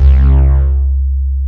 REZMOOG C2-R.wav